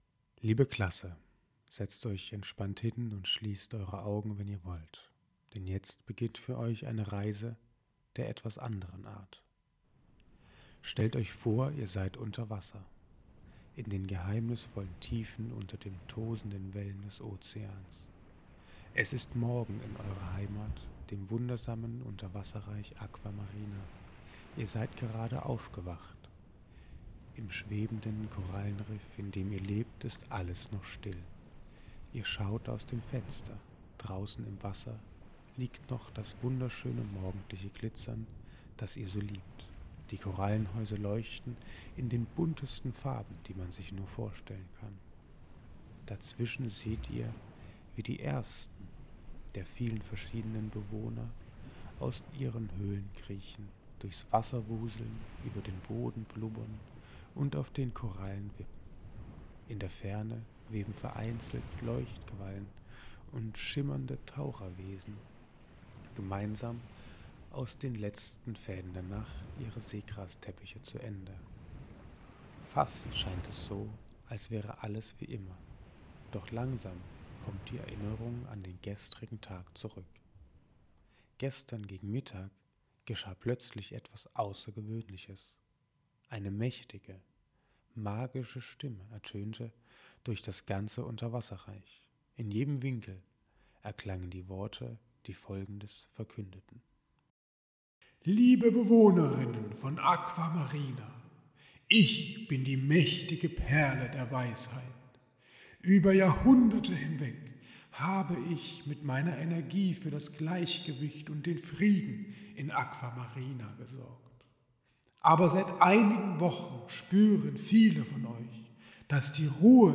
Inhaltlich gibt es keinen Unterschied, es wird jedoch empfohlen das Hörspiel zu nutzen, da es die Atmosphäre der Geschichte lebhaft darstellt.